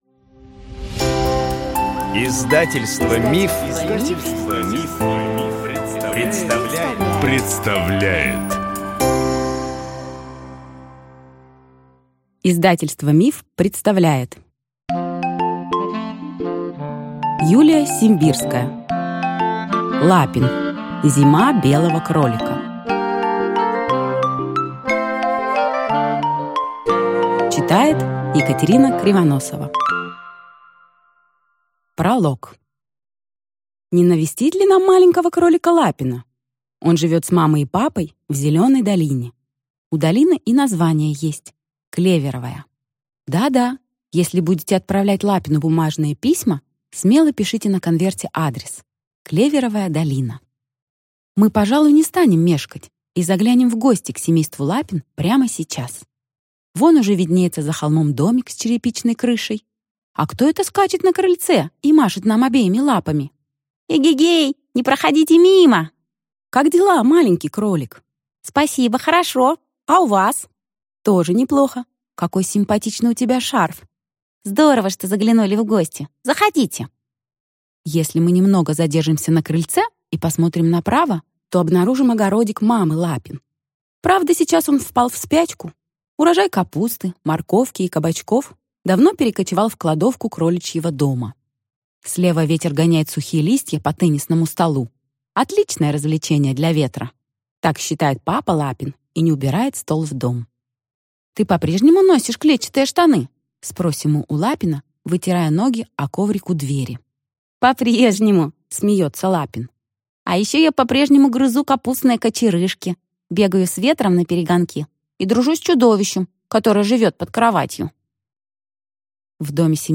Аудиокнига Лапин. Зима белого кролика | Библиотека аудиокниг